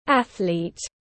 Vận động viên tiếng anh gọi là athlete, phiên âm tiếng anh đọc là /ˈæθ.liːt/
Athlete /ˈæθ.liːt/